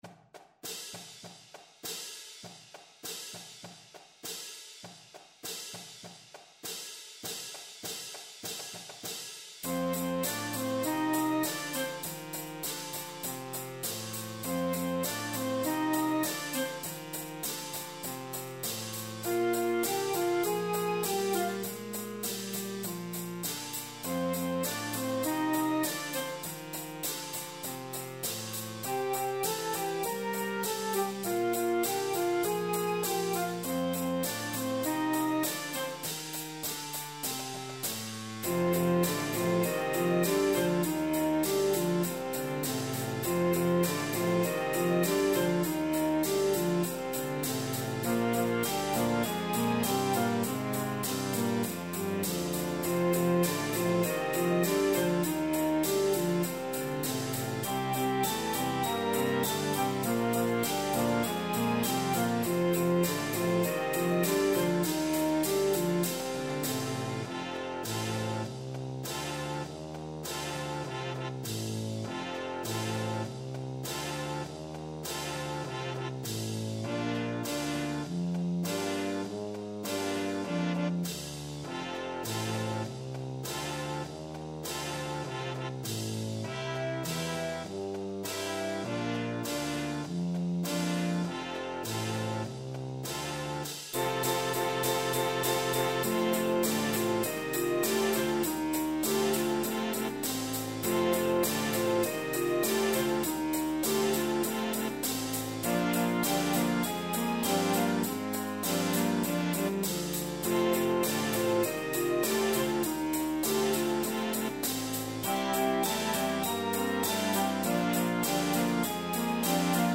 Energy, drive and fun are what this piece is all about.